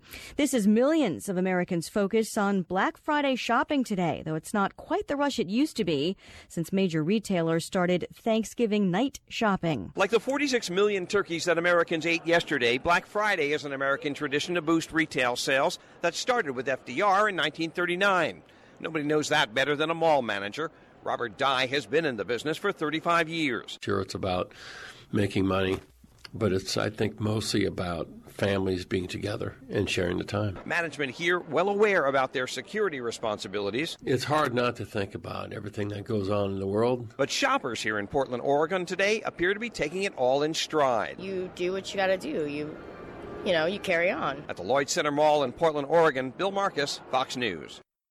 5PM-FOX-NEWS-RADIO-NEWSCAST.mp3